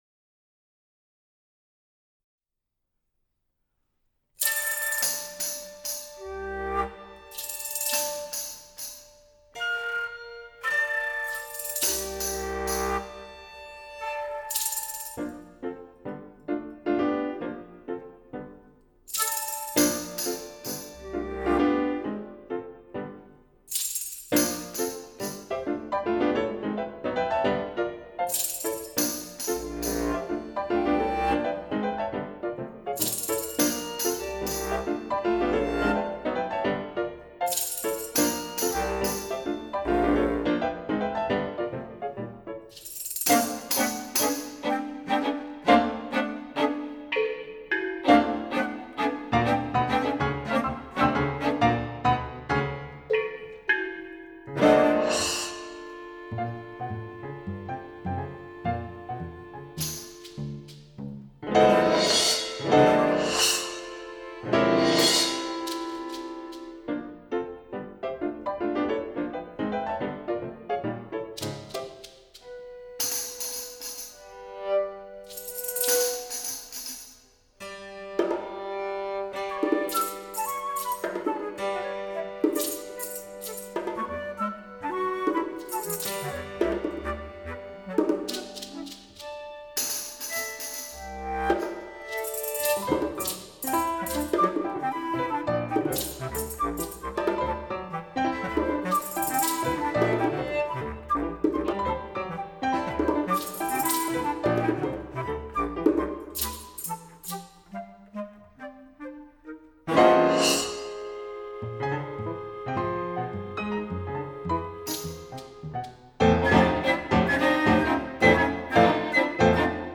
Flute, Bass Clarinet, Perc(2), Piano, Violin, Cello
Live Recording Excerpt